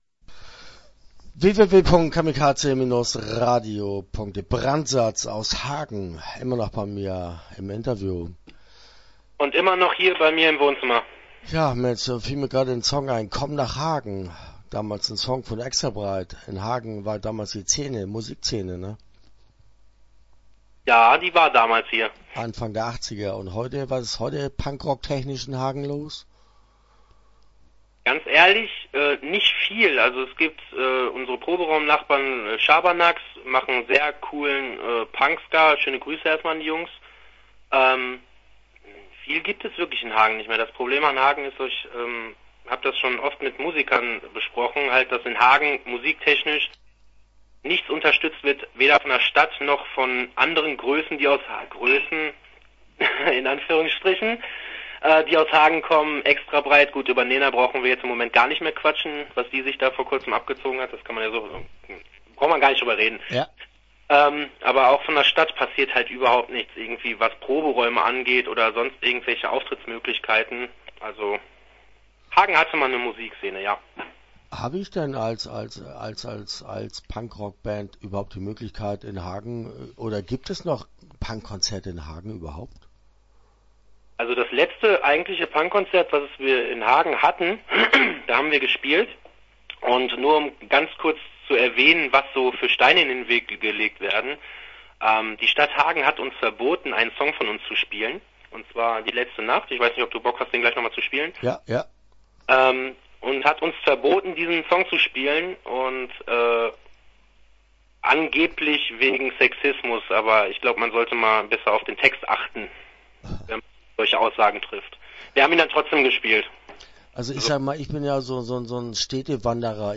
Start » Interviews » Brand!Satz!